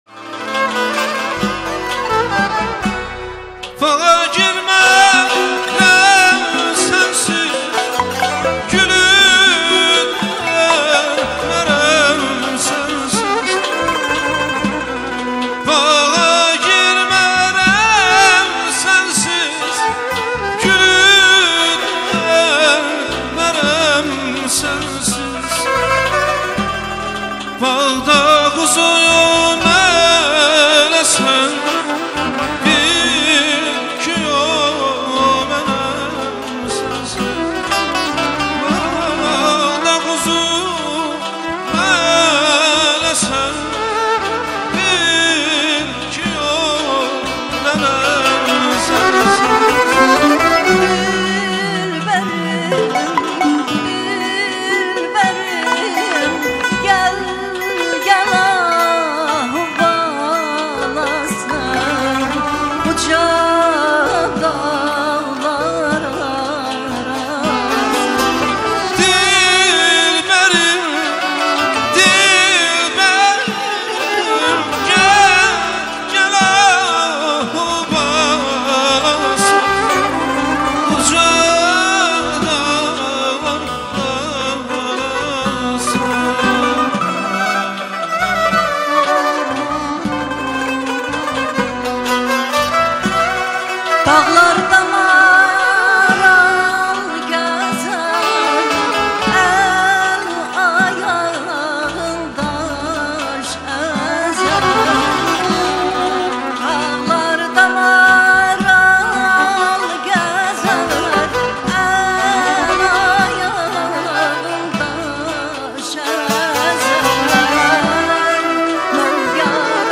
Canlı ifa